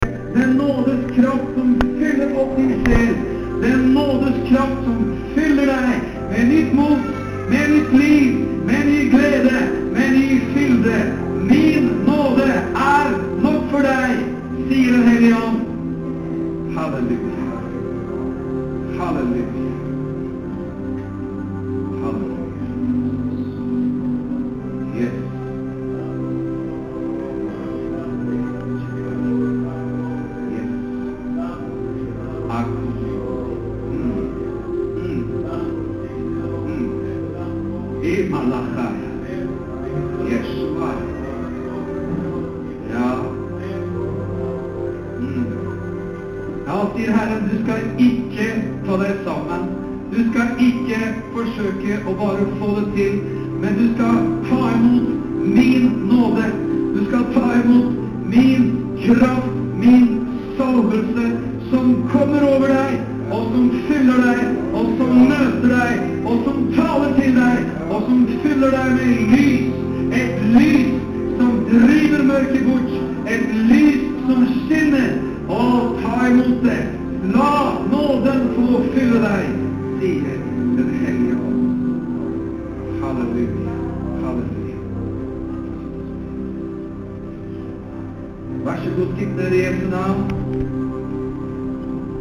JESUS CHURCHE, 19.12.10.